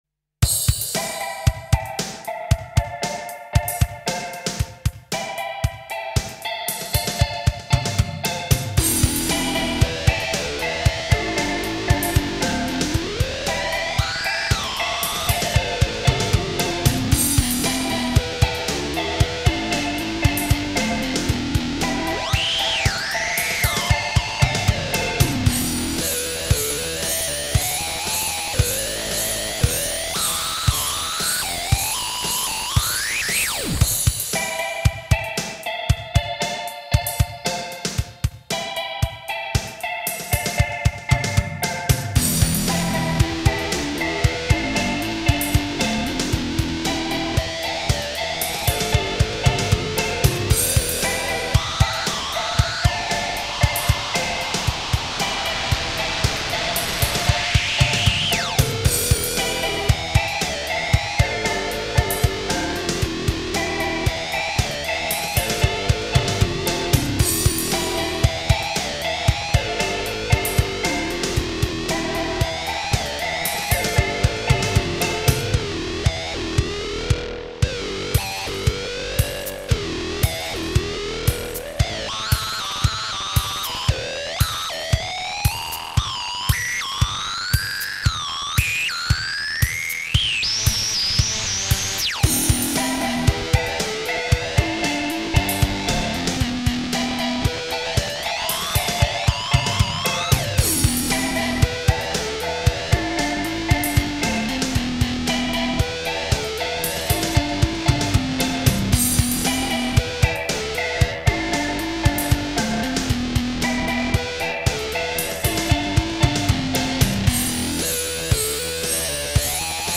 Angry sounding synth-punk